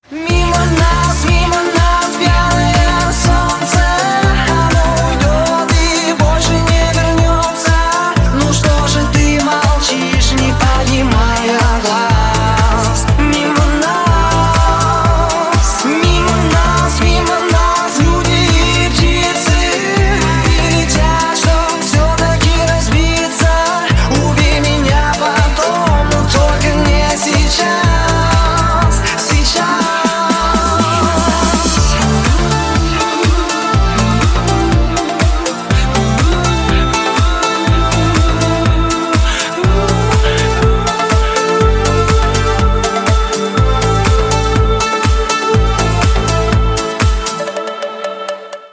• Качество: 320, Stereo
красивые